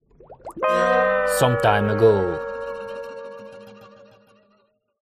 spongebob-transition-some-time-ago.mp3